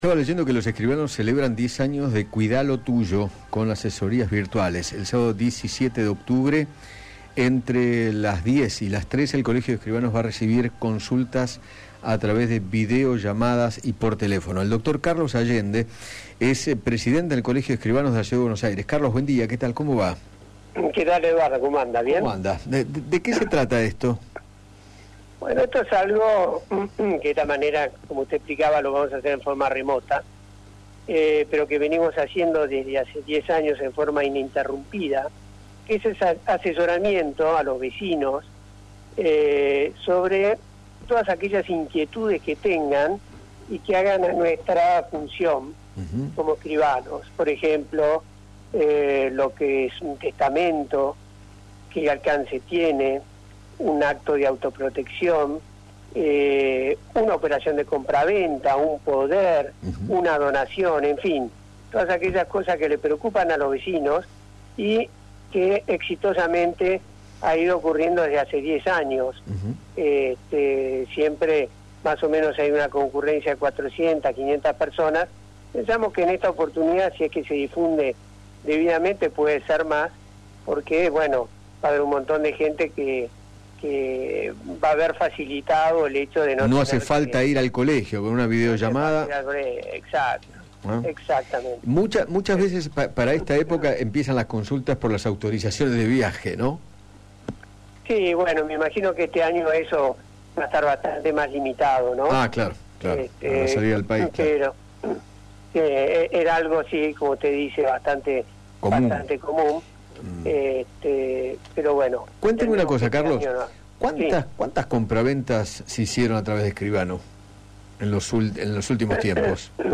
dialogó con Eduardo Feinmann sobre las consultas virtuales que darán a través de videollamadas el próximo 17 de octubre